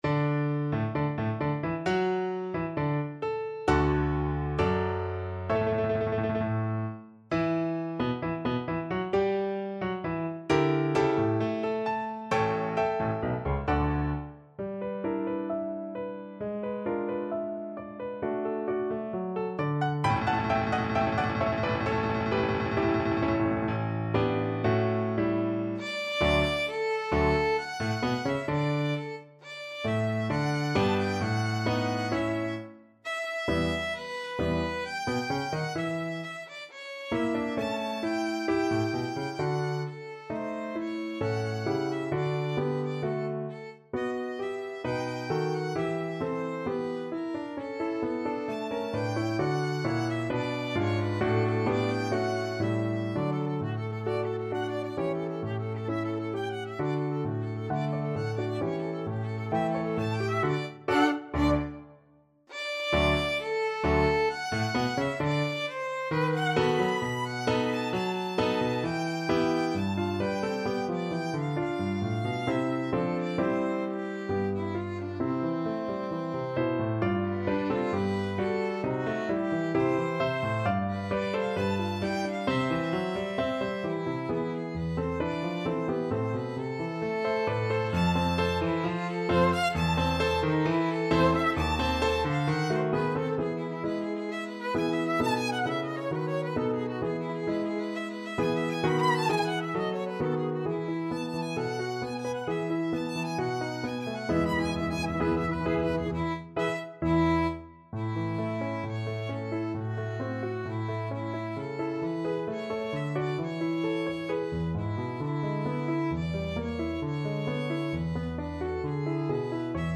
Violin
D major (Sounding Pitch) (View more D major Music for Violin )
4/4 (View more 4/4 Music)
~ = 100 Allegro moderato =132 (View more music marked Allegro)
Classical (View more Classical Violin Music)